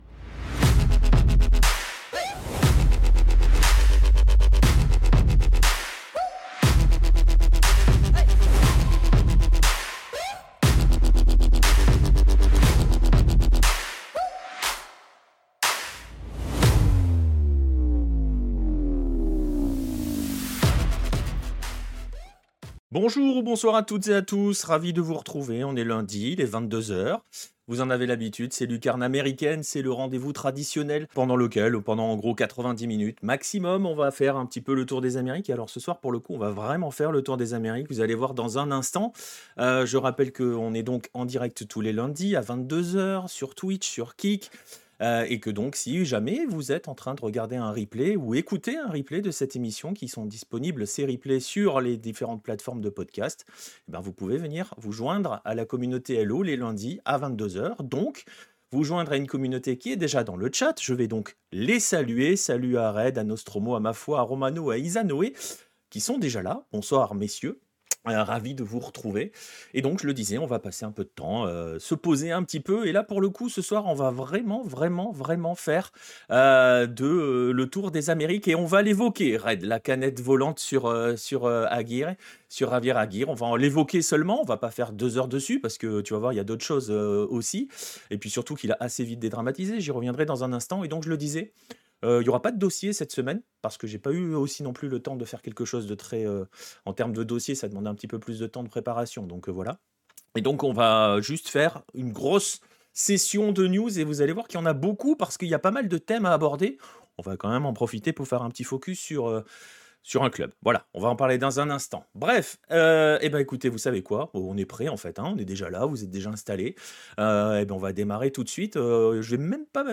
Musique Electronic Future Bass